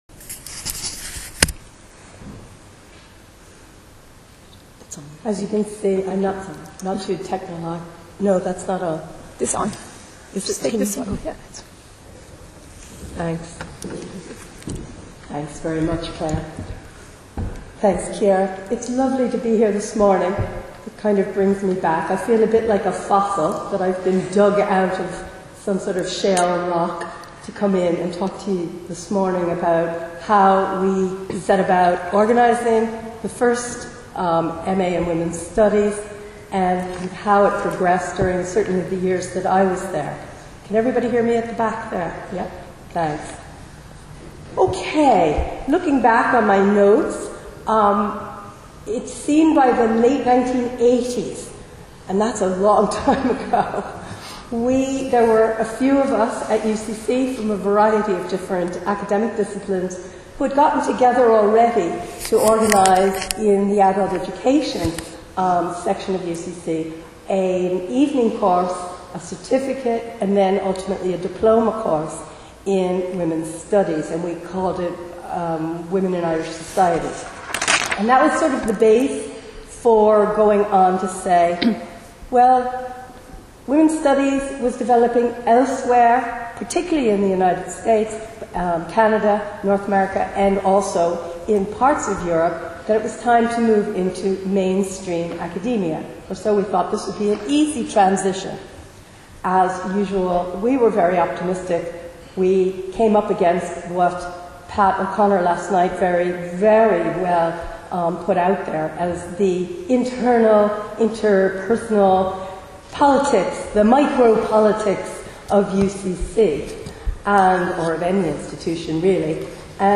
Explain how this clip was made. WomensStudiesatUCC-roundtable9.3.19.WMA